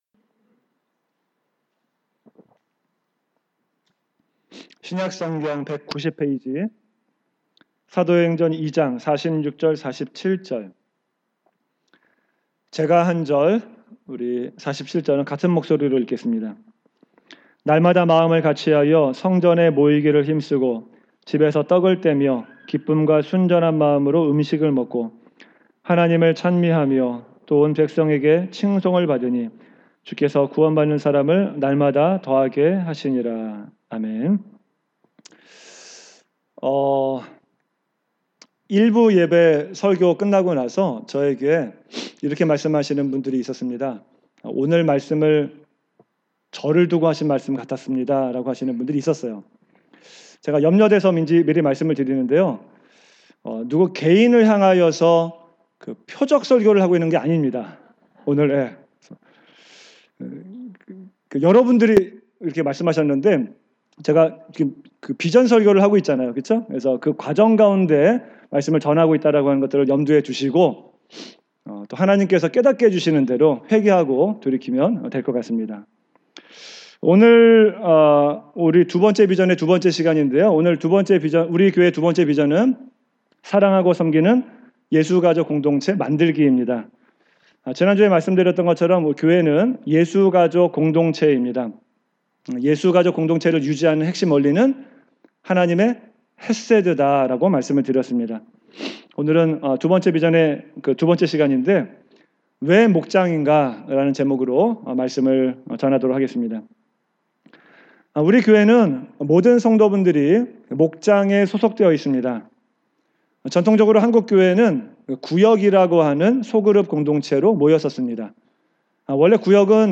Categories: 2020 주일설교